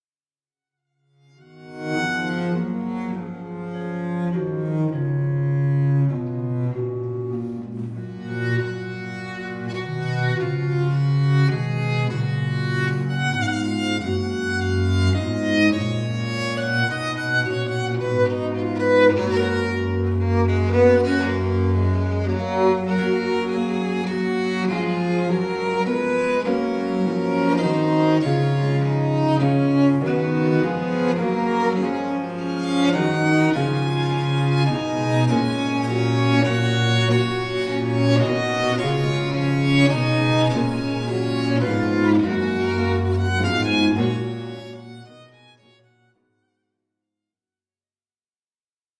ces sonates dites "du Rosaire" pour violon et basse continue